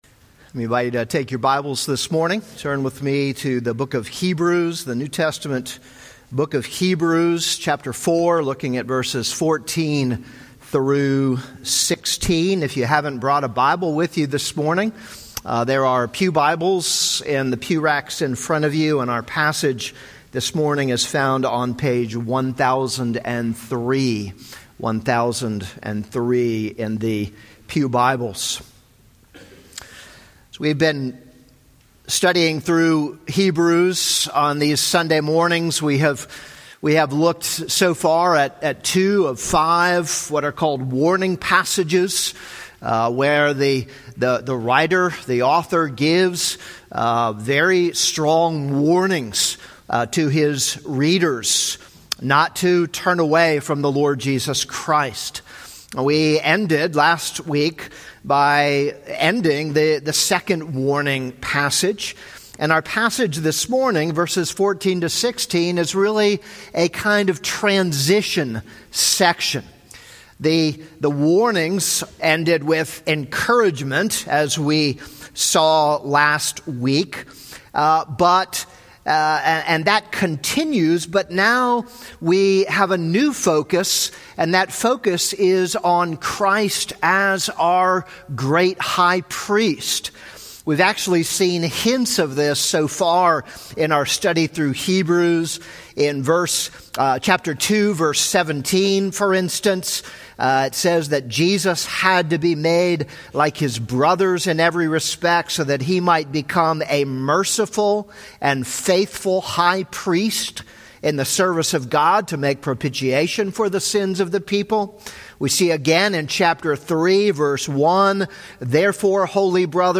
This is a sermon on Hebrews 4:14-16.